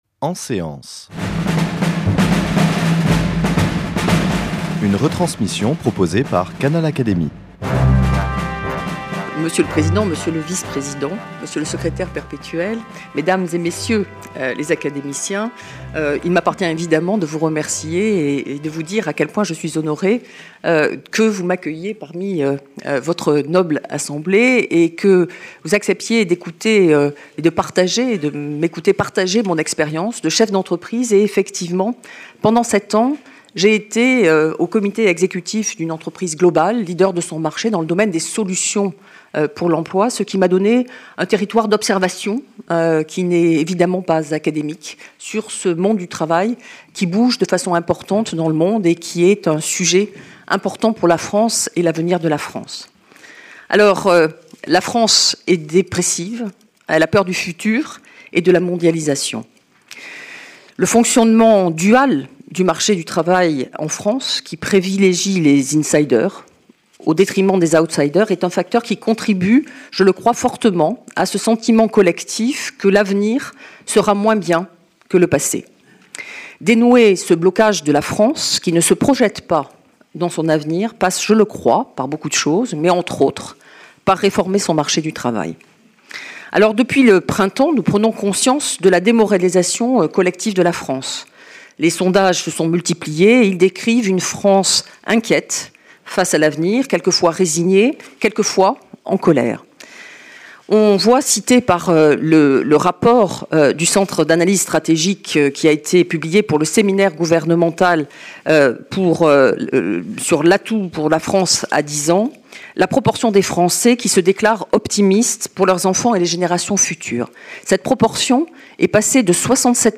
Cette séance a été enregistrée le 23 septembre 2013 au Palais de l'Institut, salle Hugot.